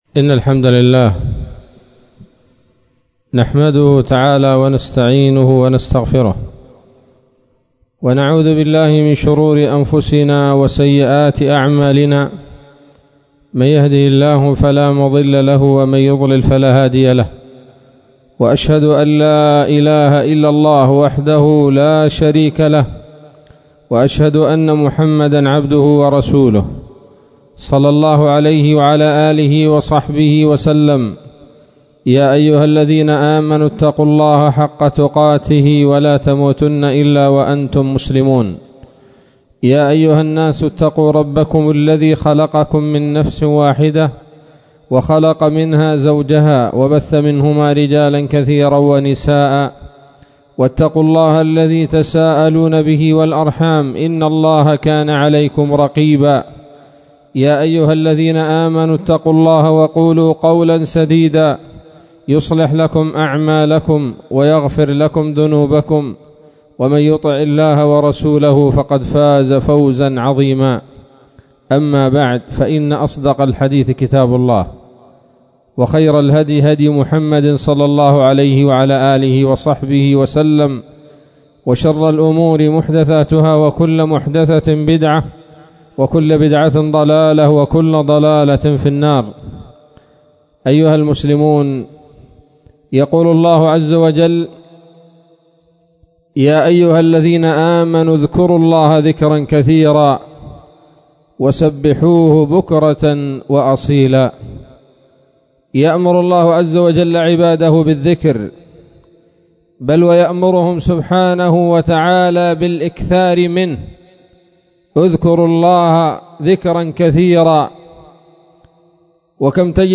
خطبة جمعة بعنوان: (( إجالة الفكر في بعض ثمار الذكر )) 6 من شهر شعبان 1442 هـ